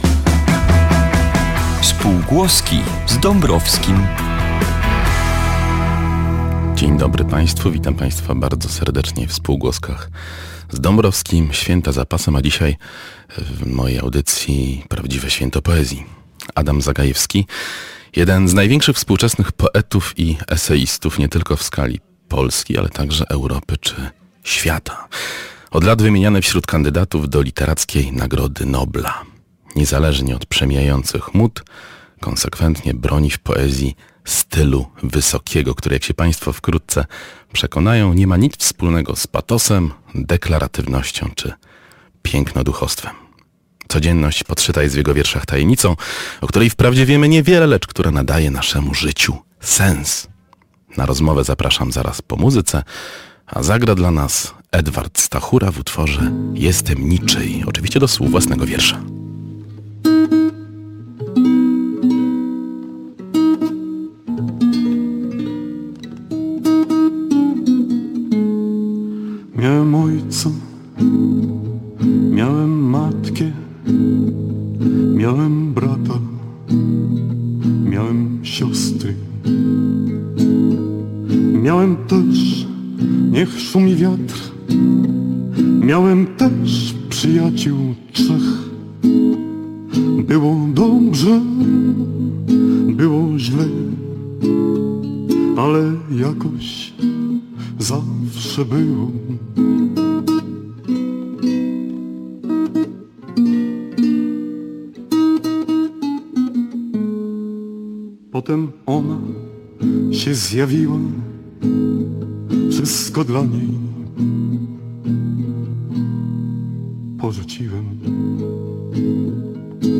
Rozmowa z Adamem Zagajewskim.